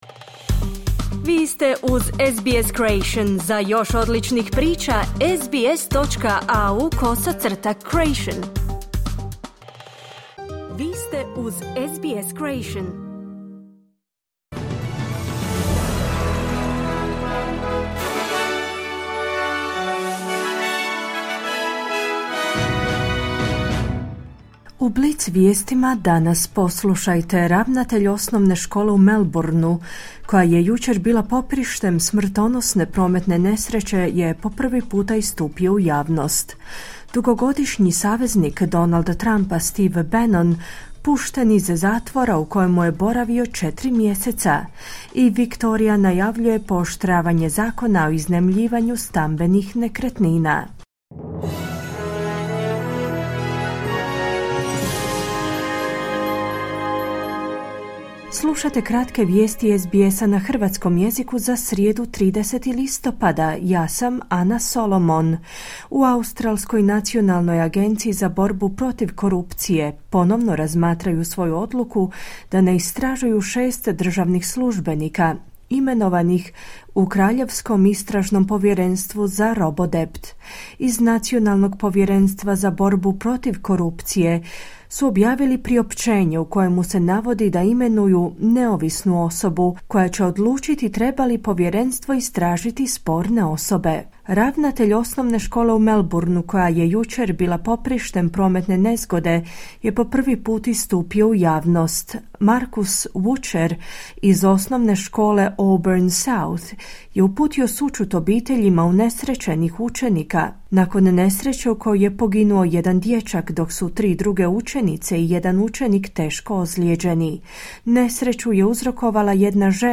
Kratke vijesti SBS-a na hrvatskom jeziku.
Vijesti radija SBS.